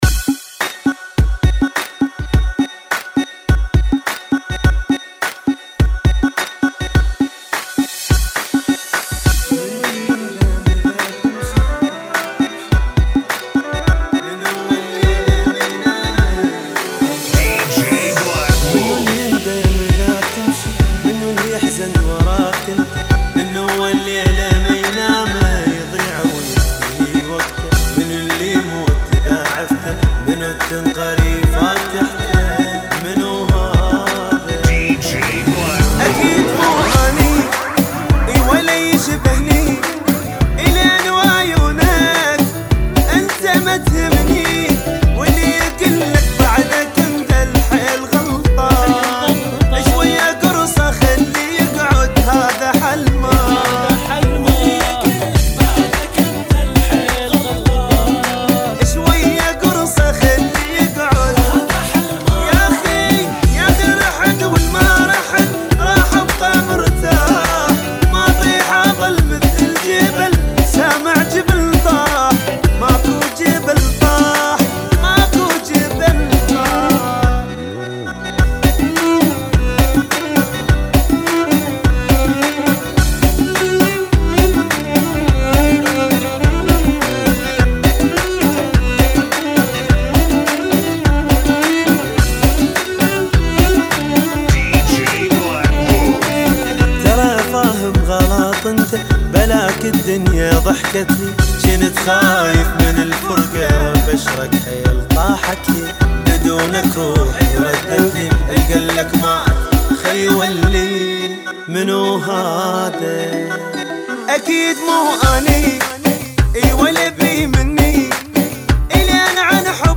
104 Bpm